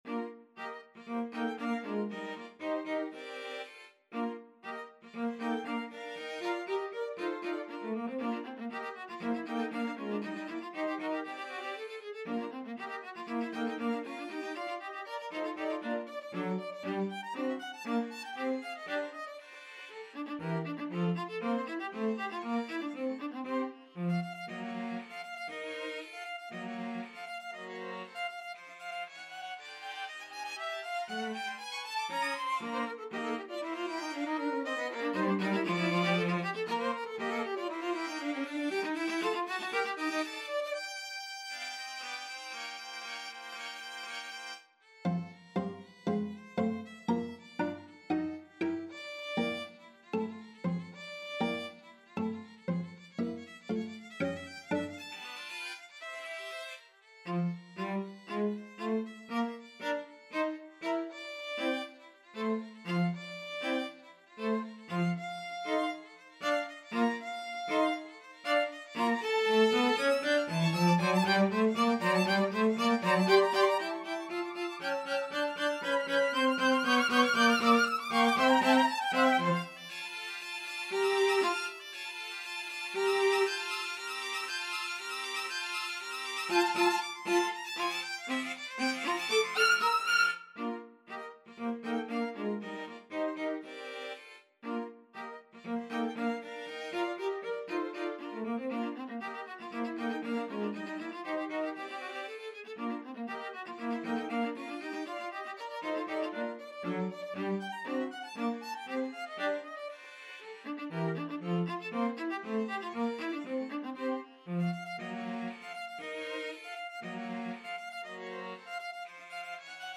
Violin 1Violin 2ViolaCelloCello (Bass Clef)
Allegro giusto = 118 (View more music marked Allegro)
Classical (View more Classical String Quartet Music)